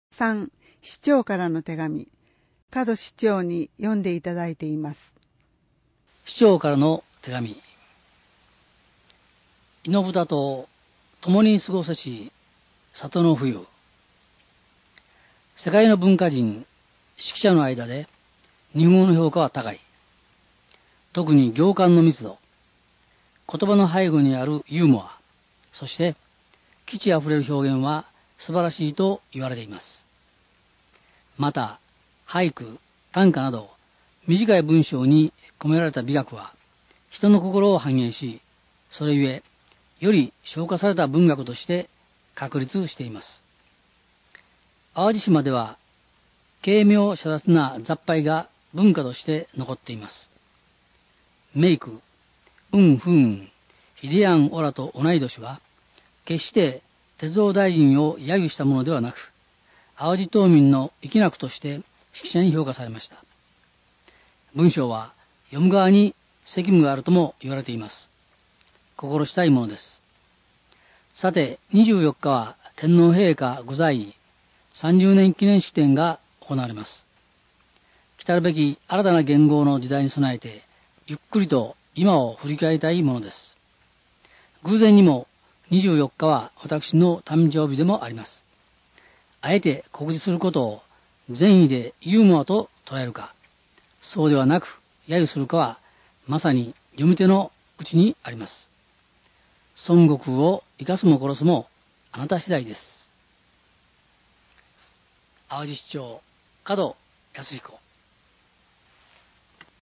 朗読　東浦朗読ボランティアグループ・ひとみの会